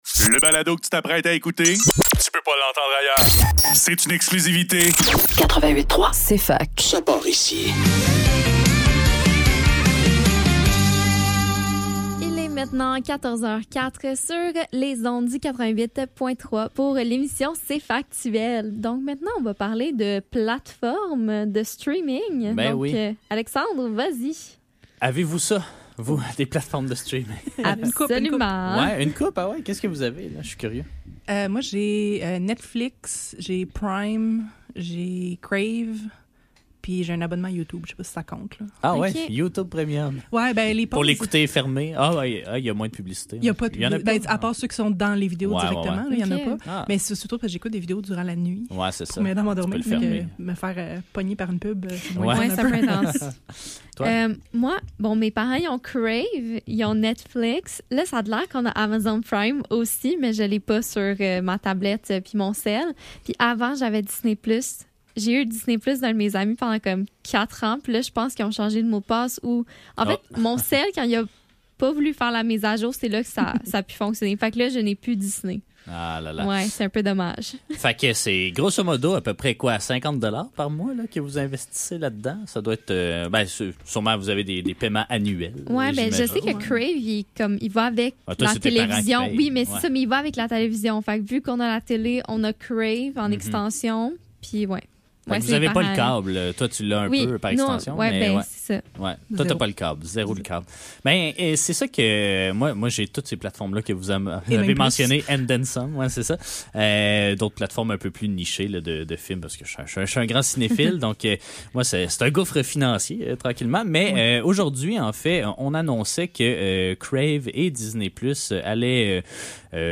Cfaktuel - Crave et Disney+ réunis: discussion sur les plateformes de streaming - 5 Juin 2025